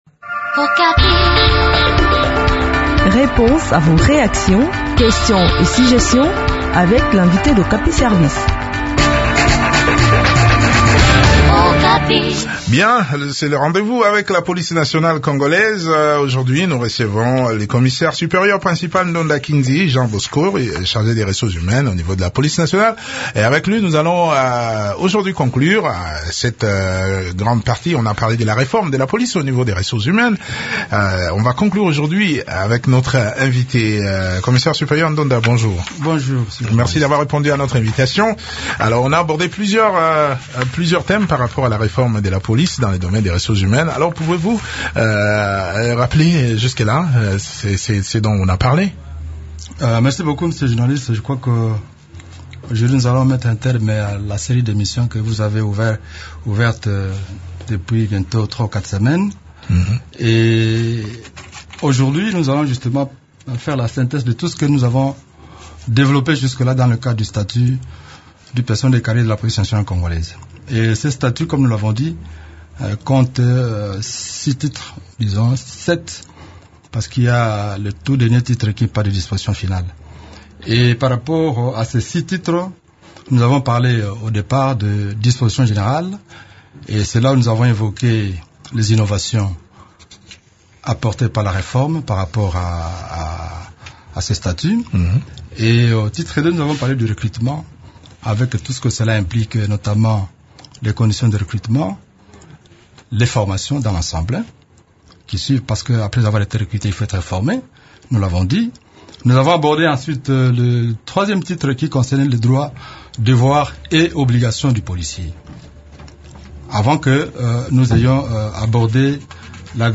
répond aux questions des auditeurs